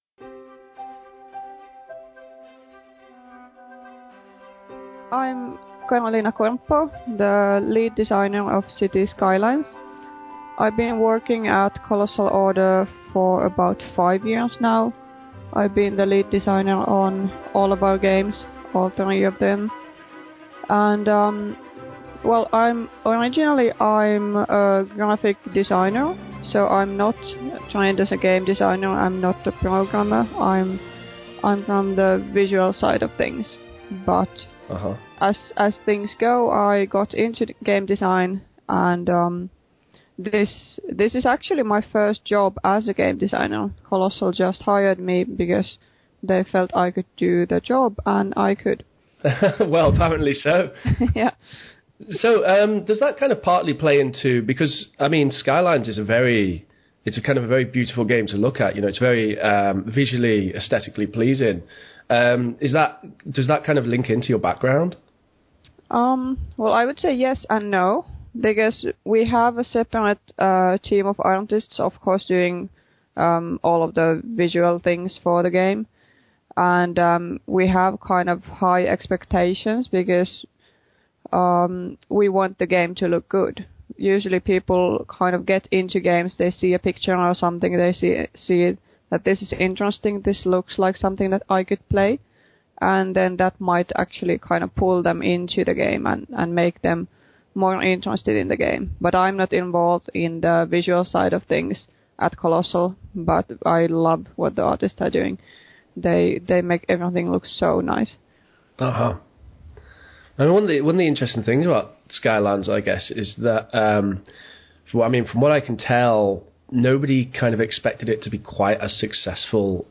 Cities: Skylines Snowfall Interview